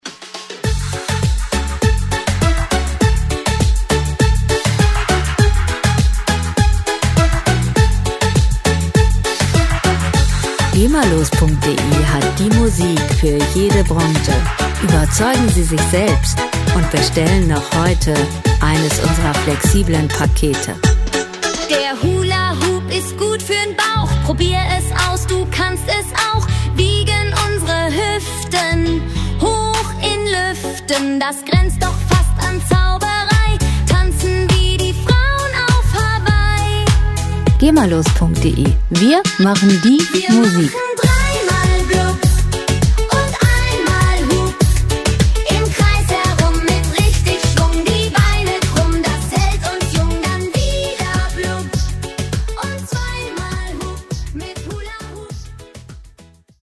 Gema-freie Kinderlieder
Musikstil: Deutschpop
Tempo: 101 bpm
Tonart: Fis-Moll
Charakter: lebendig, sportlich
Instrumentierung: Gesang, Synthesizer